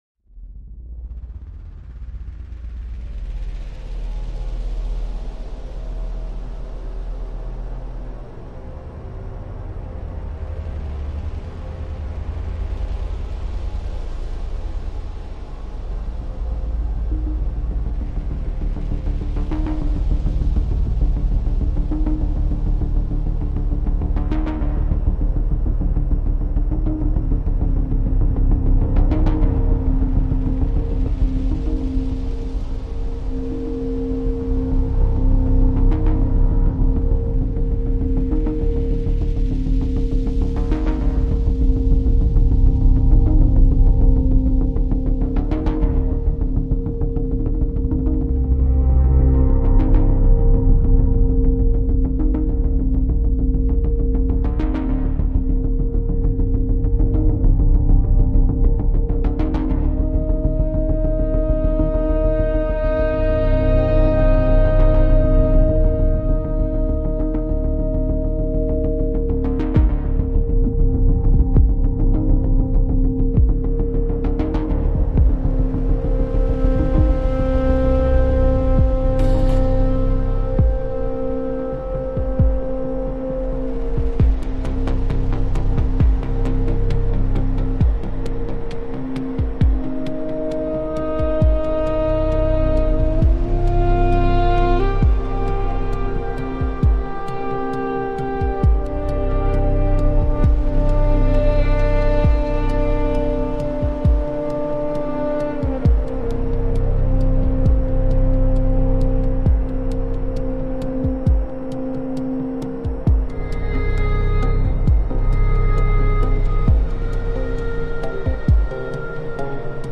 Night Stalkers - Electronic - Young Composers Music Forum